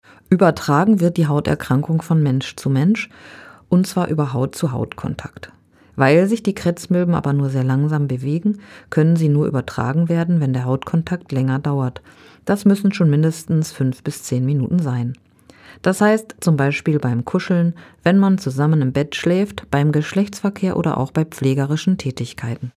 O-Töne02.05.2024